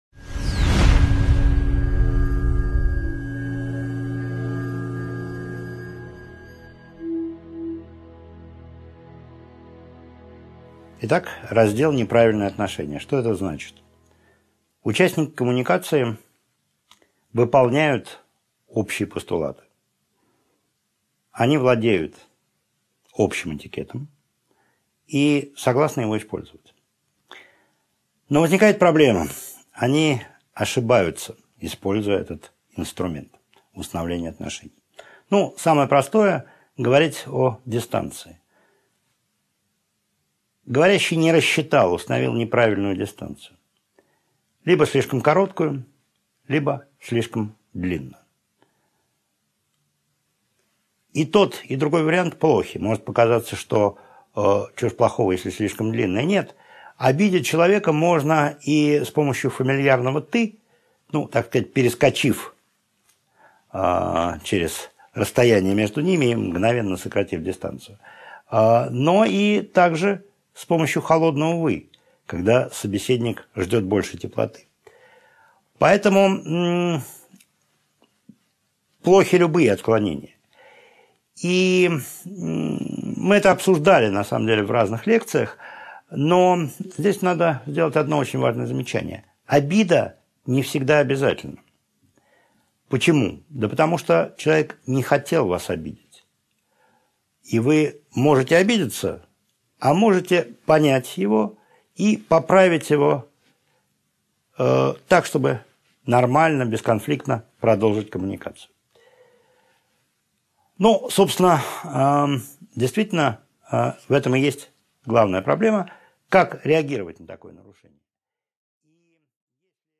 Aудиокнига 9.6 Неправильные отношения Автор Максим Анисимович Кронгауз.